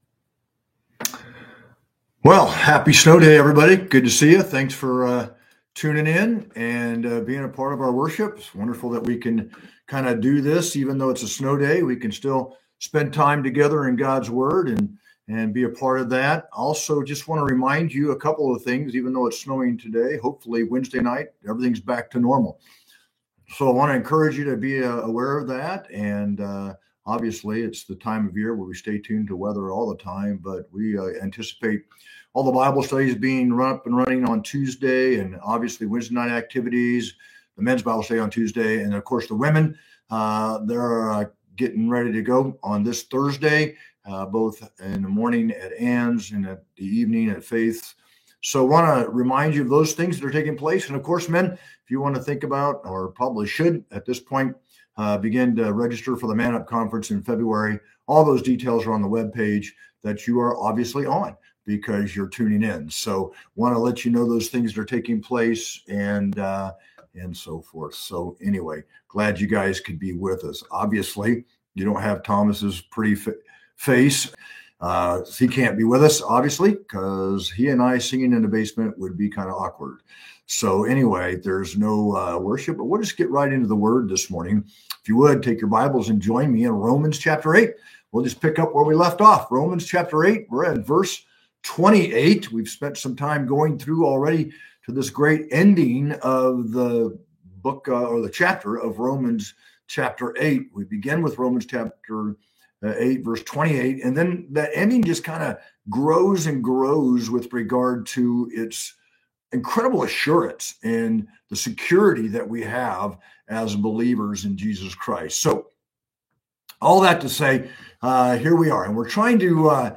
sermon-1-5-25.mp3